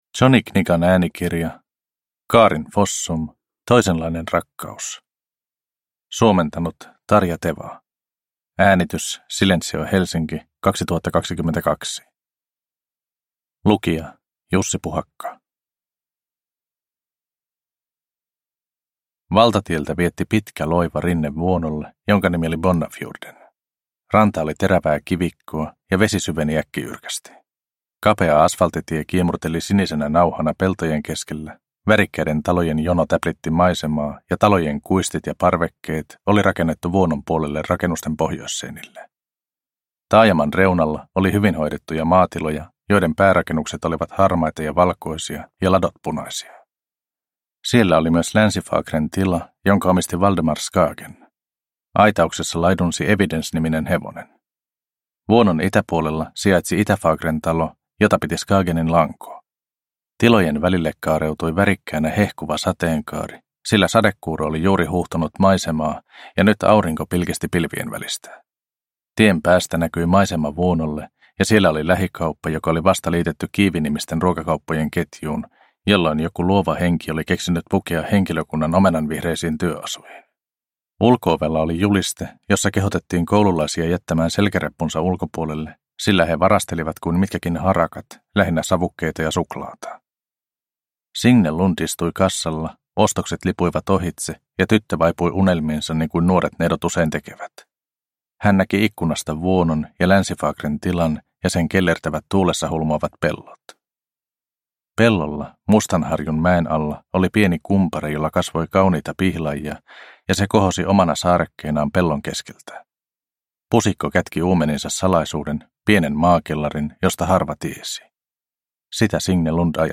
Toisenlainen rakkaus – Ljudbok – Laddas ner